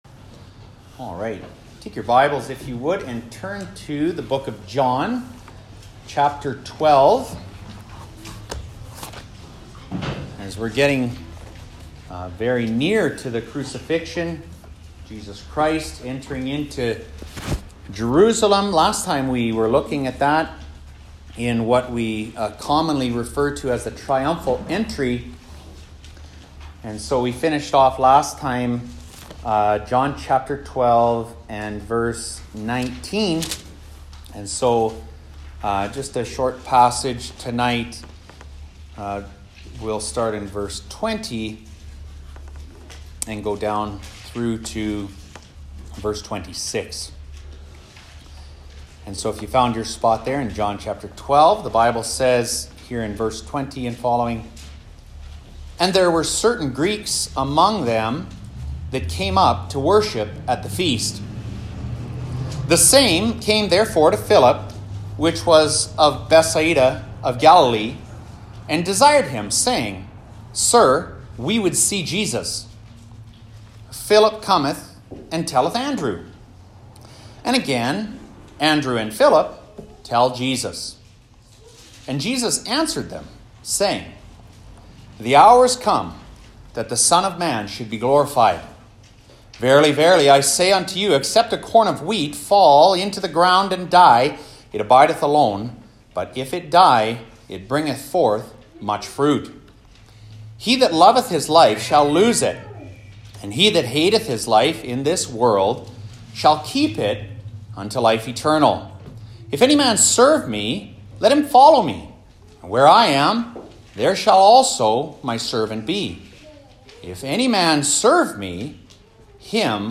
Sermons | Harvest Baptist Church